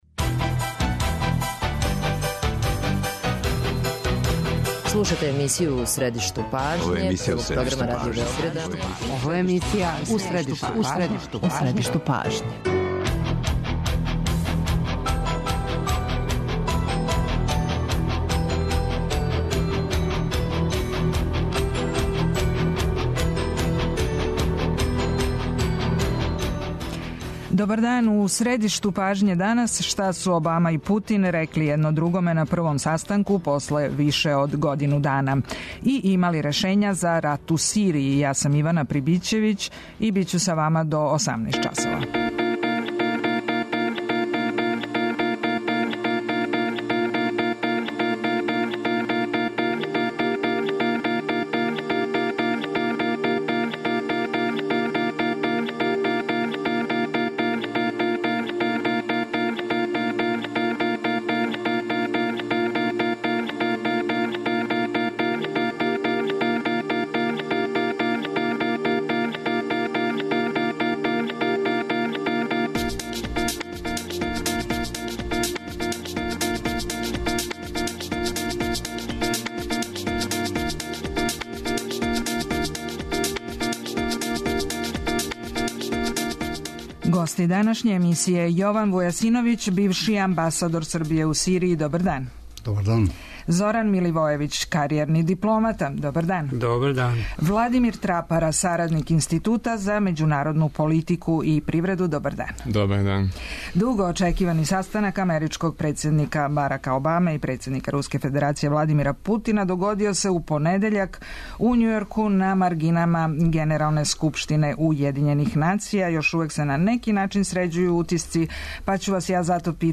доноси интервју са нашим најбољим аналитичарима и коментаторима